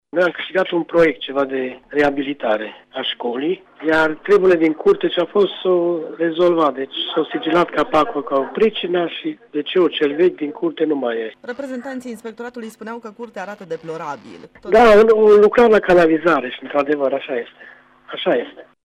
Viceprimarul din Nadeș, Florin Balu, a declarat că problema cu decantorul și cu toaleta a fost rezolvată, însă curtea unității de învățământ este afectată de lucrările la canalizare, aflate în plină desfășurare: